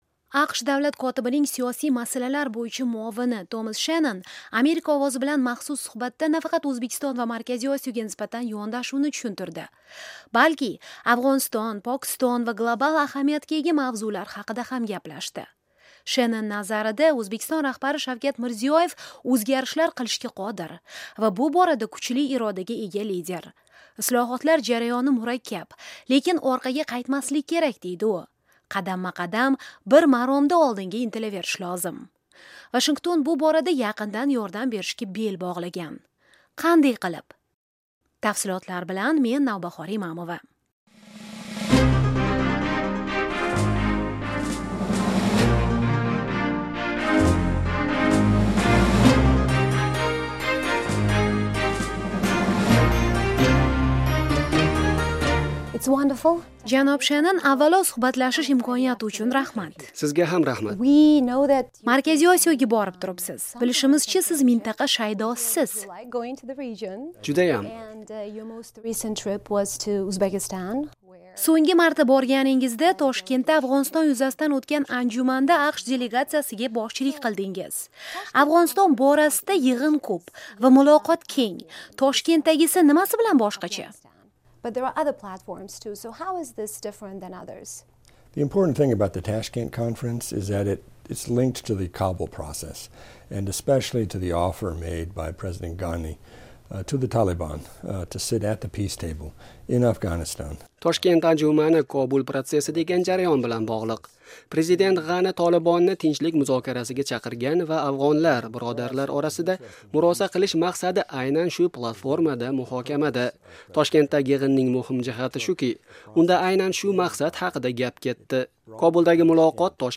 AQSh-Markaziy Osiyo: Davlat katibi muavini bilan suhbat
AQSh Davlat kotibining Siyosiy masalalar bo'yicha muovini Tomas Shennon "Amerika Ovozi" bilan maxsus suhbatda nafaqat O'zbekiston va Markaziy Osiyoga nisbatan yondashuvni tushuntirdi, balki Afg'oniston, Pokiston va global ahamiyatga ega mavzular haqida ham gaplashdi.